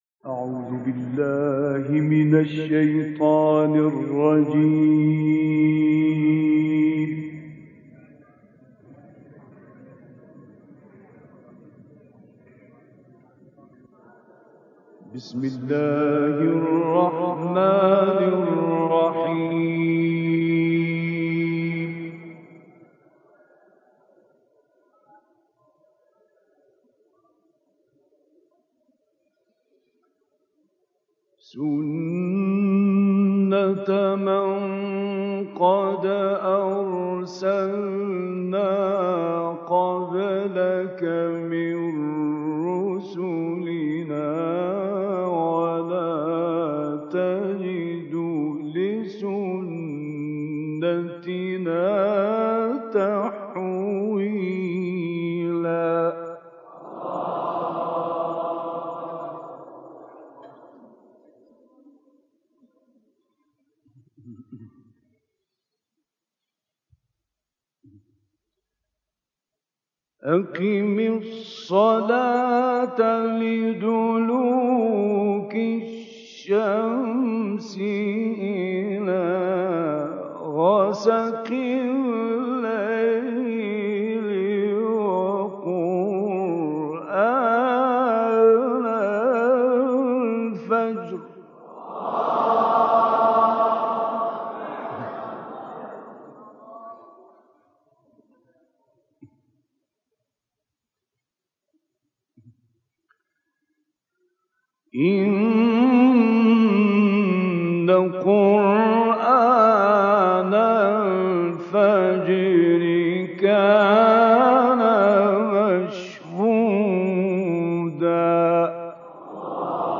تلاوتی زیبا و دلنشین از استاد راغب مصطفی غلوش
تلاوت آیات 77 تا 87 سوره اسراء و سوره قدر توسط مرحوم راغب مصطفی غلوش.